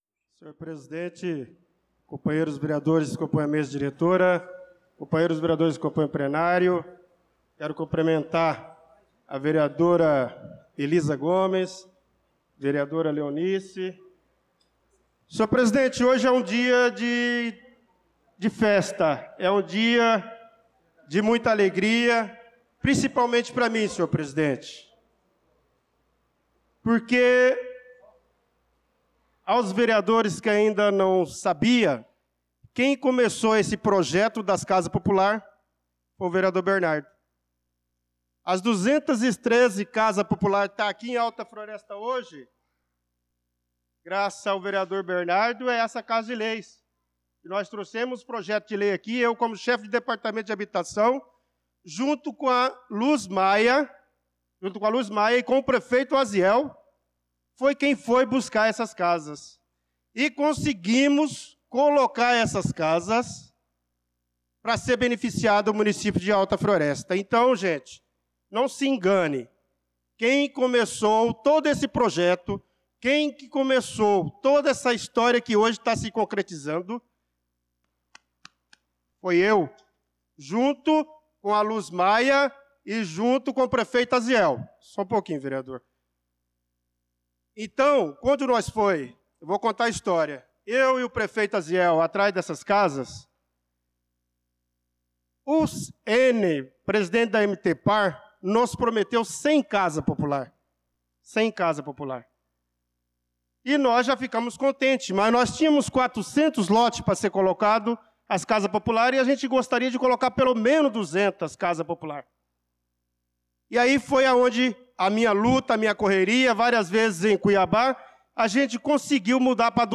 Pronunciamento do vereador Bernardo Patrício na Sessão Ordinária do dia 02/06/2025